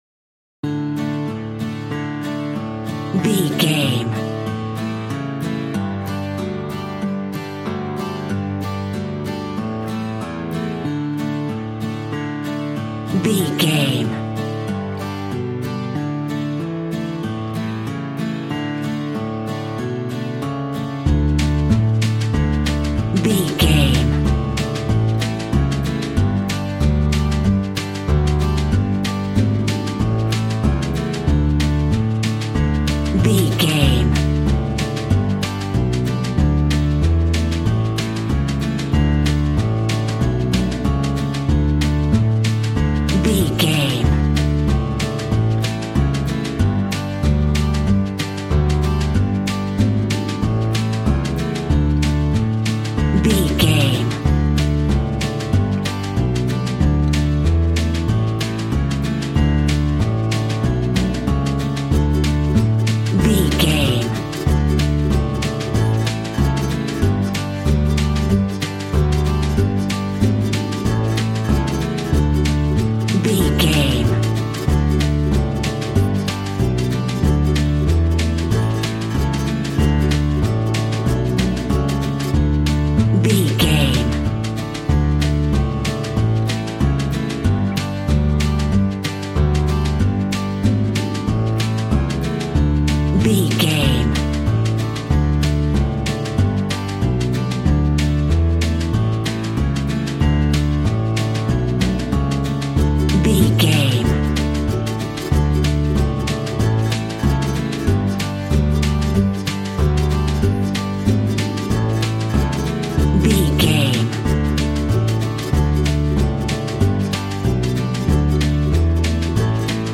Ionian/Major
D
Fast
bouncy
positive
double bass
drums
acoustic guitar